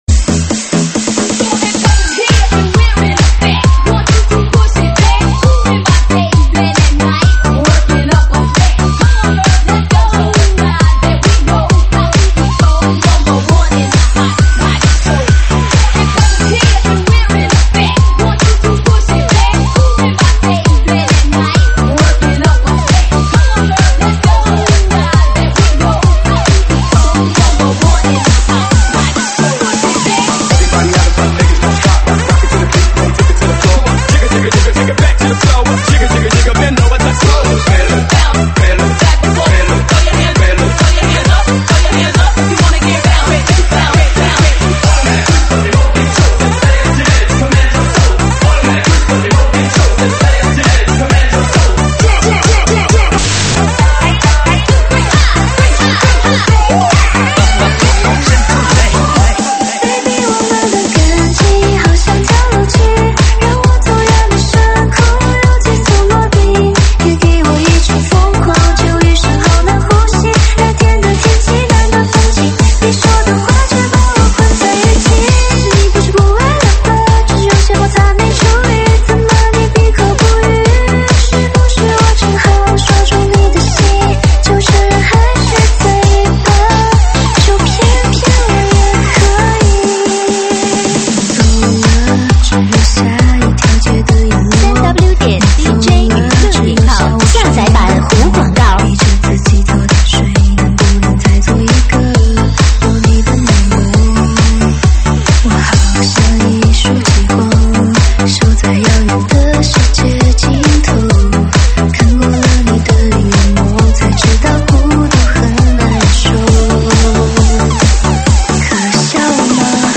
电子Electro